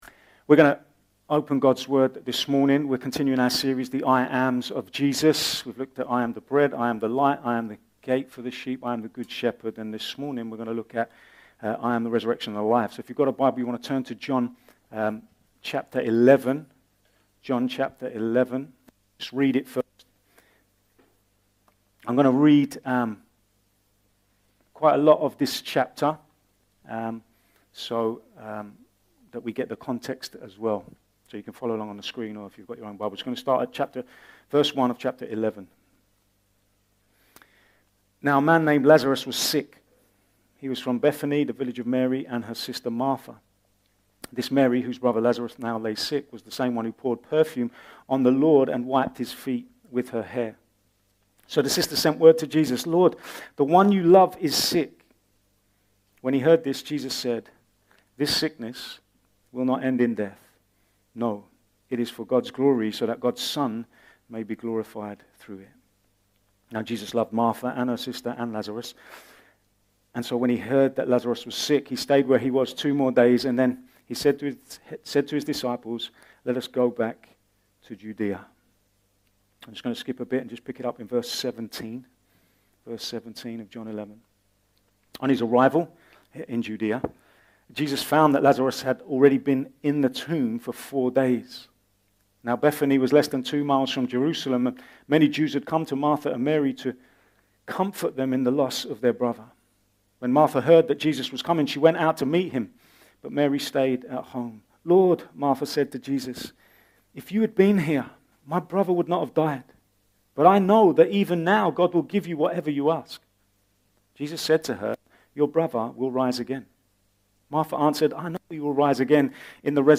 A message from the series "Sunday Morning." We continue our series exploring the 'I am' claims of Jesus, this week looking at his claim to be the resurrection and the life, considering the hope that this truth should flood our lives with.